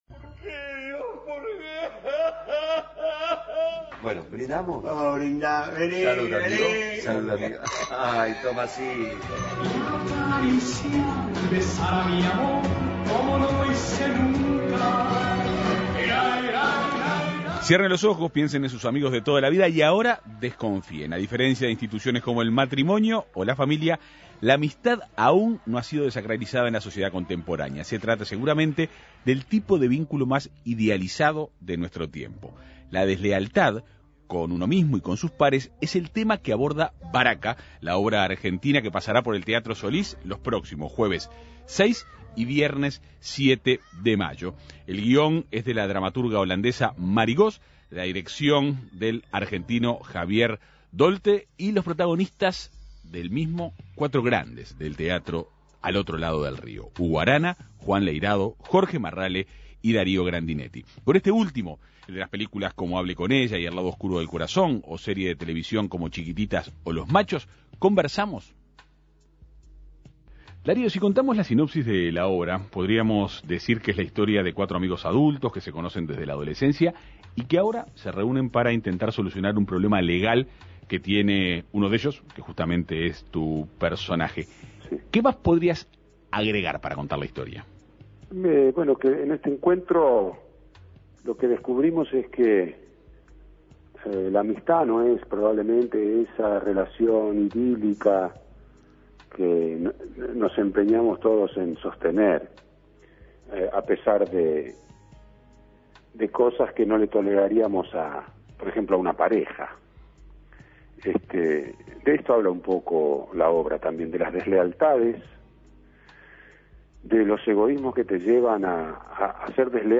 La deslealtad, con uno mismo y con sus pares, es el tema que aborda Baraka, la obra argentina que pasará por el Teatro Solís el jueves 6 y el viernes 7 de mayo. El guión es de la dramaturga holandesa María Goos, la dirección de Javier Daulte y los protagónicos están a cargo de cuatro grandes del teatro argentino: Hugo Arana, Juan Leyrado, Jorge Marrale y Darío Grandinetti, quien dio detalles de la obra a En Perspectiva Segunda Mañana.